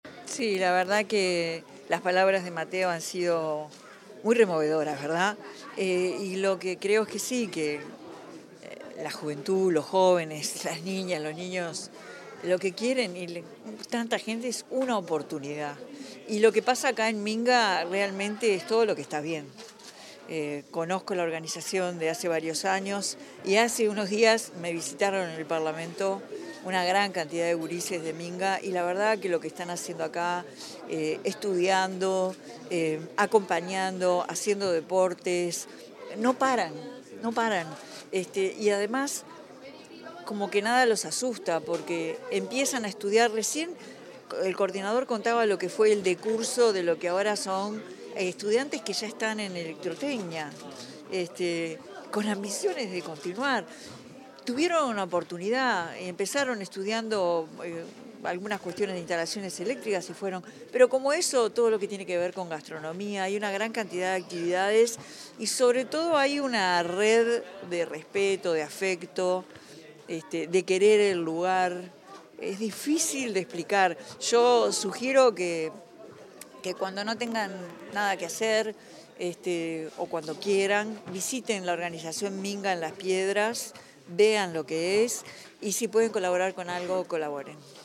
Declaraciones de la presidenta de la República en ejercicio, Carolina Cosse
La presidenta de la República en ejercicio, Carolina Cosse, dialogó con la prensa tras participar en la celebración de los 15 años del Movimiento